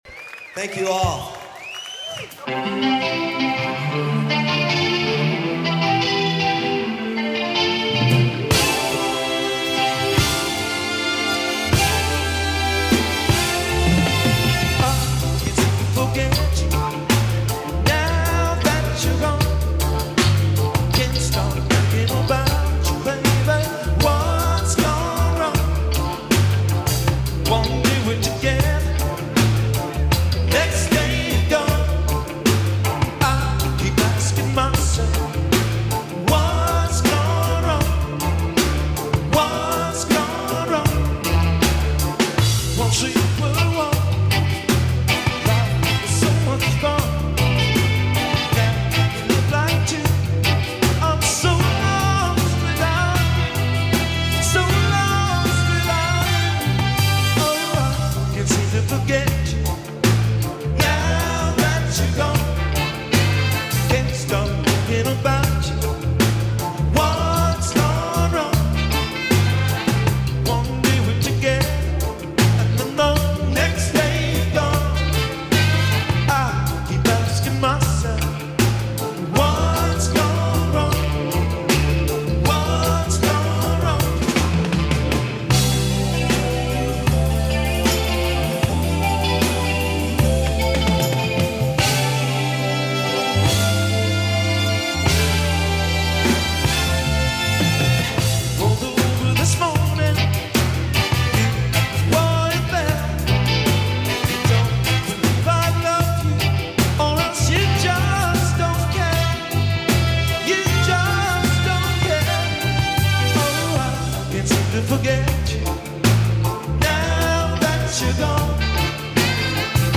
Ska from America.
America’s first Ska Band